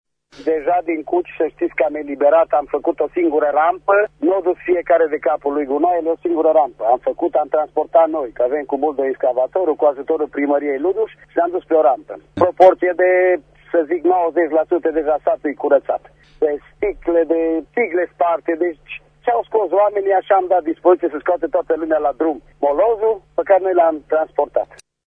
Primarul comunei Cuci, Ilie Șuta:
Extras din emisiunea Sens Unic
Primar-Cuci.mp3